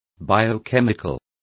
Complete with pronunciation of the translation of biochemical.